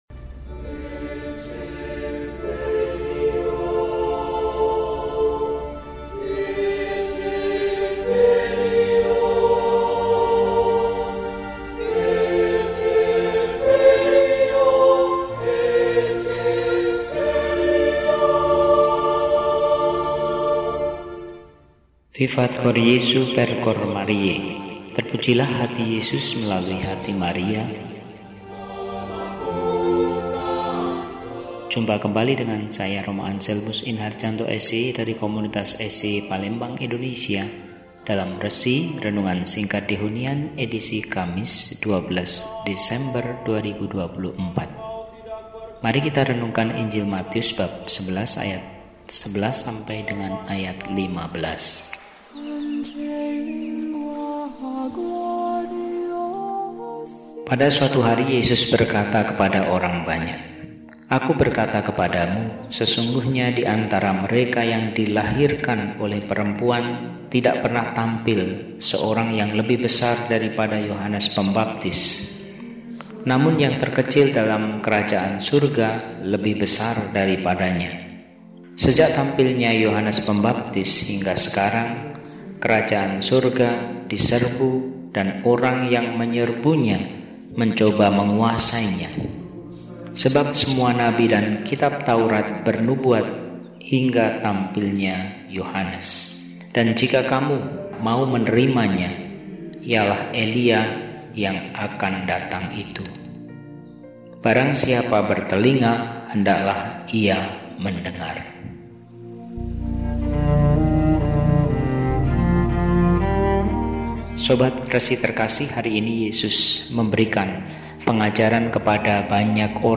Kamis, 12 Desember 2024 – Hari Biasa Pekan II Adven – RESI (Renungan Singkat) DEHONIAN